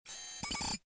scan2.ogg